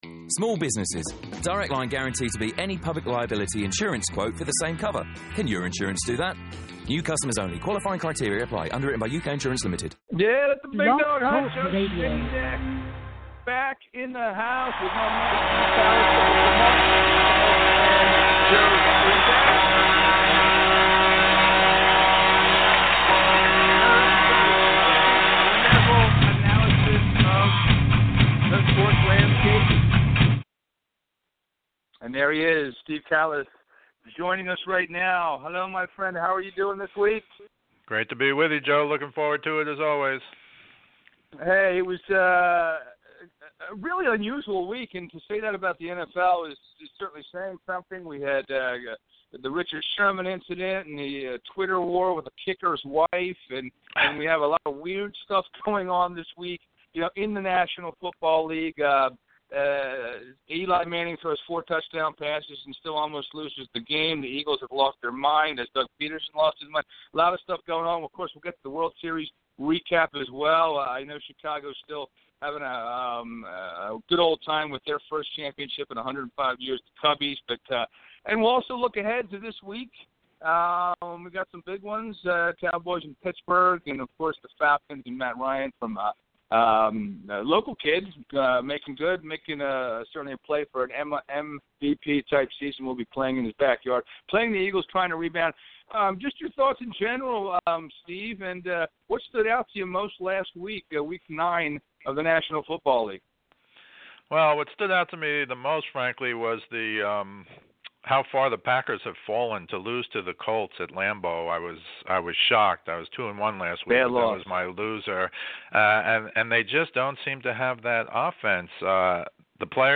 Sports call in sho